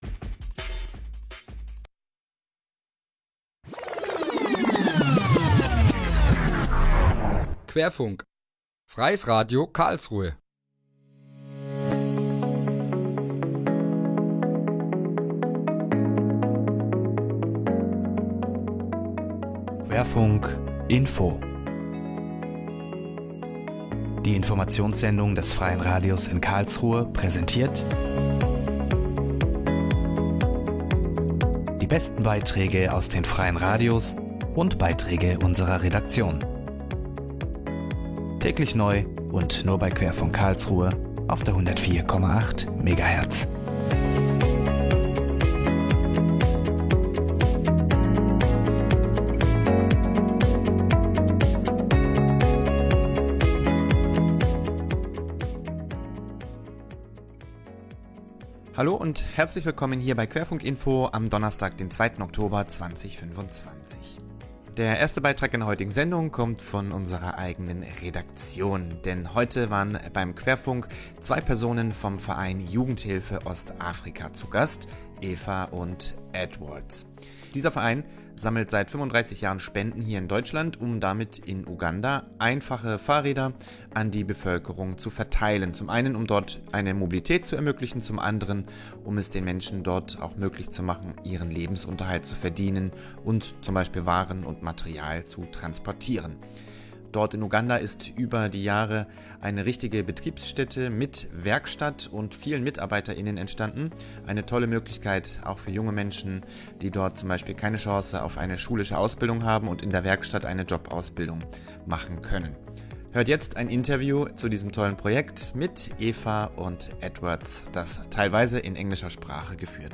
Radiointerview bei Radio Querfunk